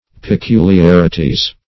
n.; pl. Peculiarities (p[-e]*k[=u]l`y[a^]r"[i^]*t[i^]z).